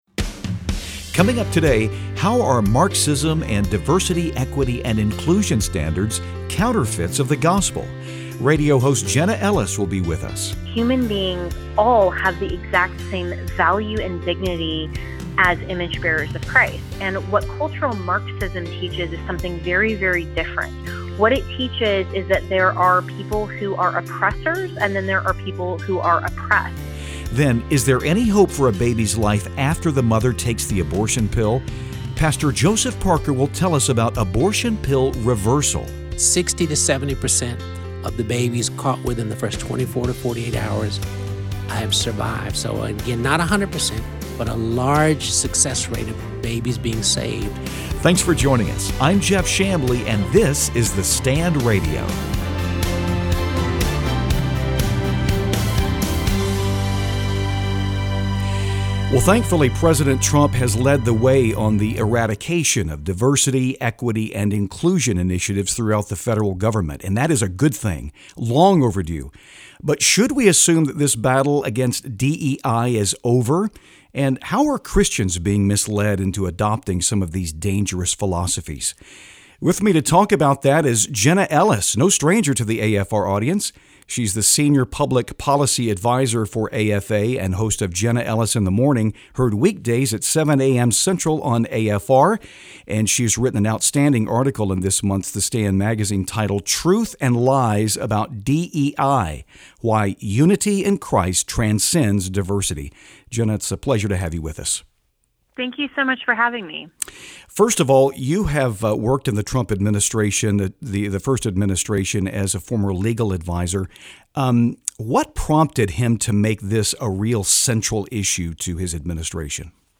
In this episode, we'll learn how Cultural Marxism and diversity, equity, and inclusion (DEI) are counterfeits of what the gospel offers for human reconciliation. Jenna Ellis, host of Jenna Ellis in the Morning, is our guest.